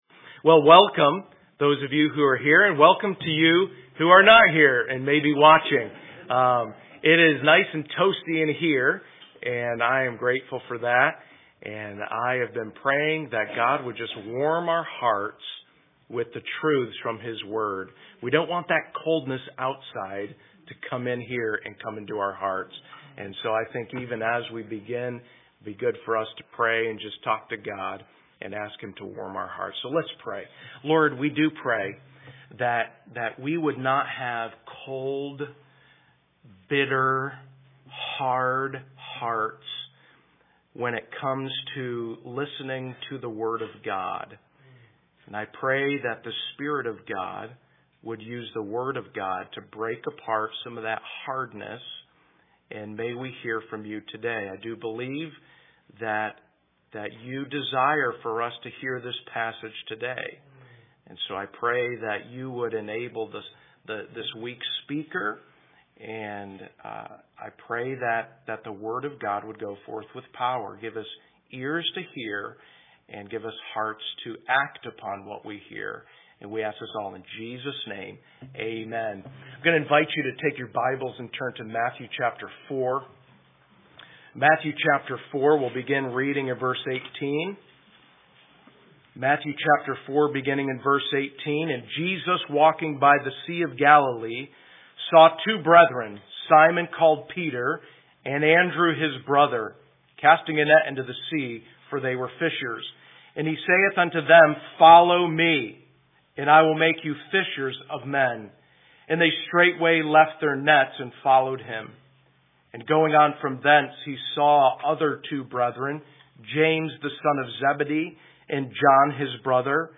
AM Messages